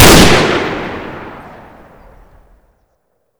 sol_reklam_link sag_reklam_link Warrock Oyun Dosyalar� Ana Sayfa > Sound > Weapons > DRAGUNOV Dosya Ad� Boyutu Son D�zenleme ..
WR_fire.wav